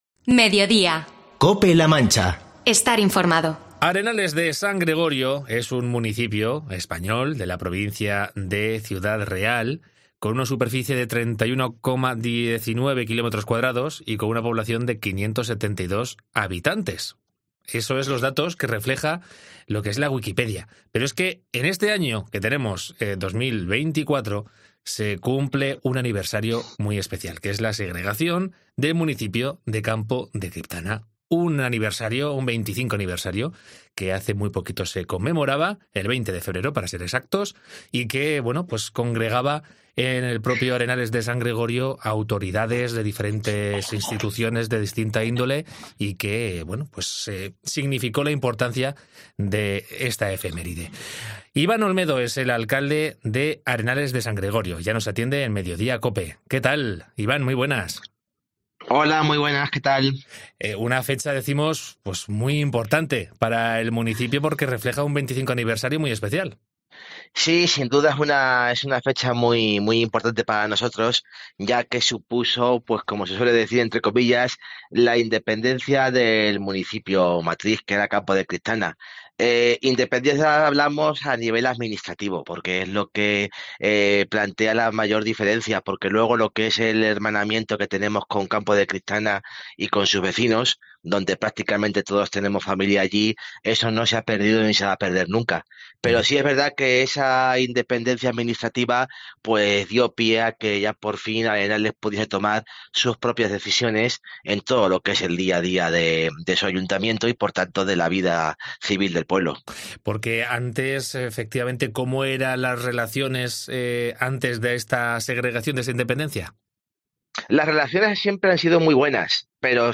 Entrevista con Iván Olmedo, alcalde de Arenales de San Gregorio con motivo del 25 aniversario como municipio independiente
Hoy, en Mediodía Cope, hemos charlado con el alcalde de Arenales de San Gregorio, Iván Olmedo, ya que la localidad ciudadrealeña ha cumplido 25 años desde su segregación de Campo de Criptana y constitución como municipio independiente de la provincia.